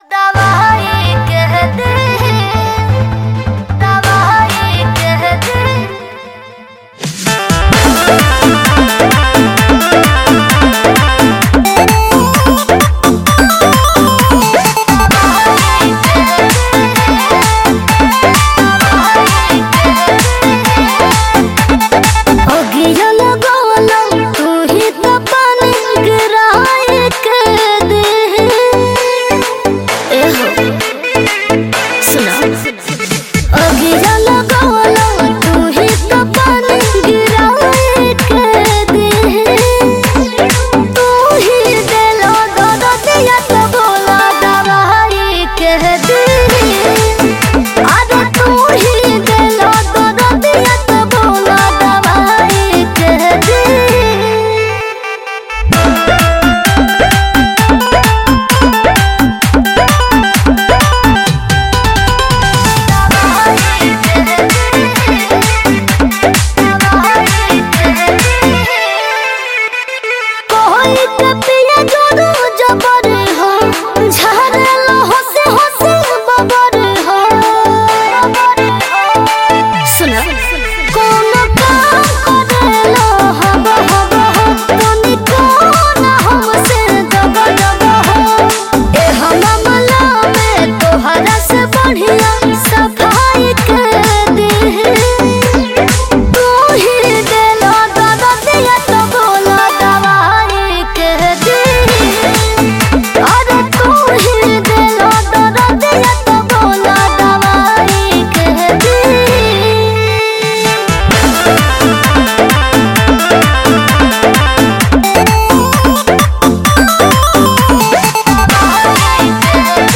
Bhojpuri Mp3 Songs